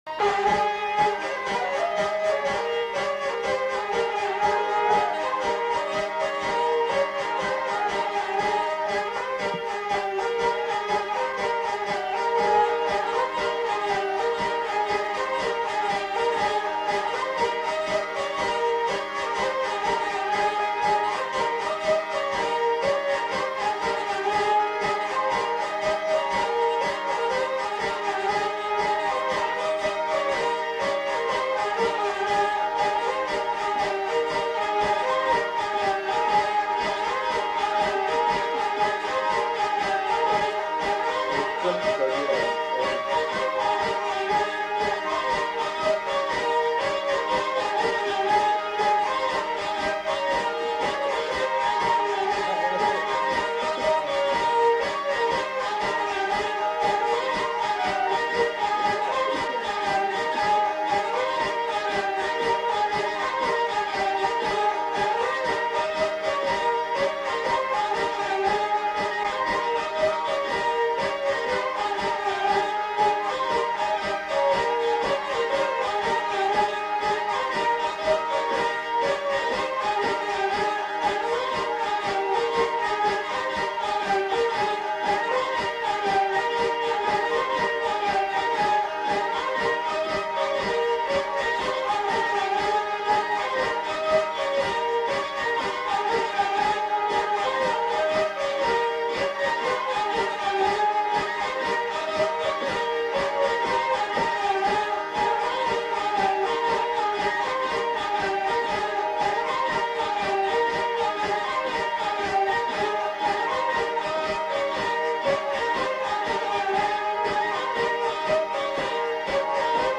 Aire culturelle : Bas-Armagnac
Lieu : Mauléon-d'Armagnac
Genre : morceau instrumental
Instrument de musique : vielle à roue
Danse : rondeau
Notes consultables : Enchaînement de deux thèmes.